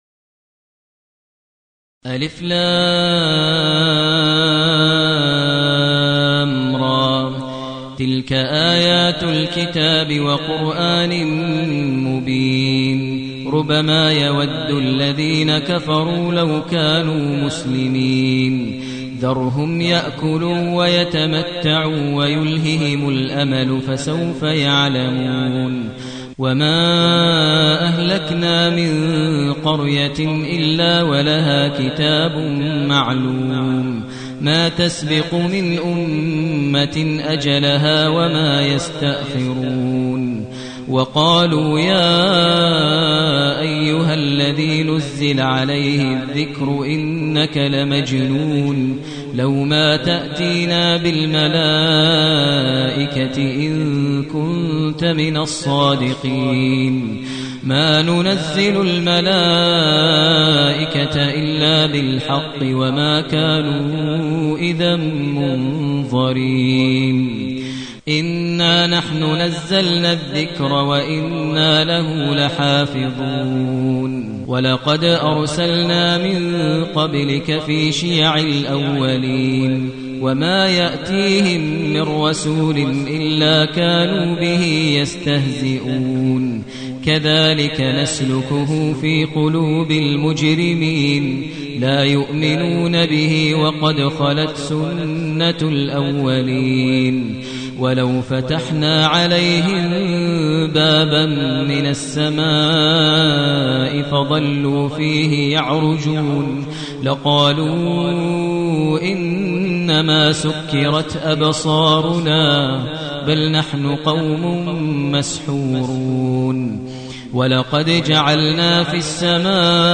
المكان: المسجد النبوي الشيخ: فضيلة الشيخ ماهر المعيقلي فضيلة الشيخ ماهر المعيقلي الحجر